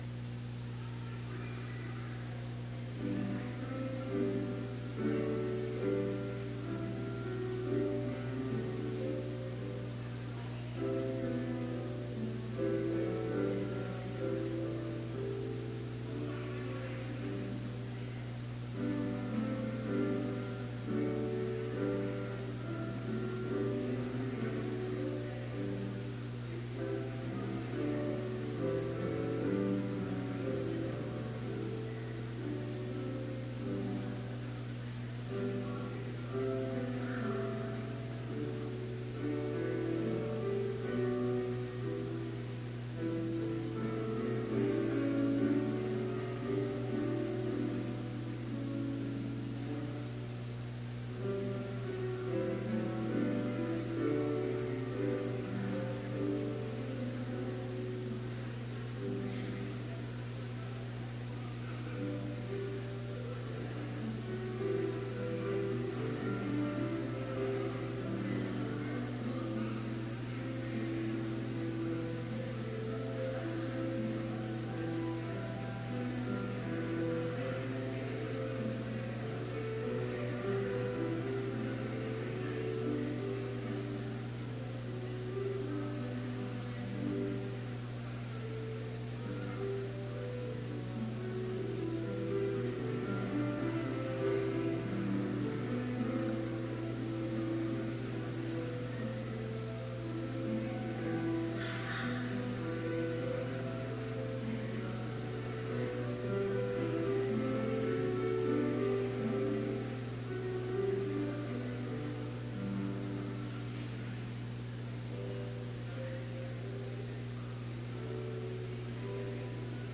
Complete service audio for Chapel - September 14, 2018